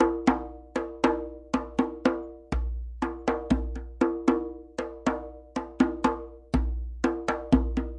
Westafrican Drumensemble " djembe grooves fanga2 120bpm
描述：这是我在我的非洲鼓上播放的一个基本的方格律。记录在我的家里。
Tag: 韵律 打击乐器 非洲鼓 非洲 加纳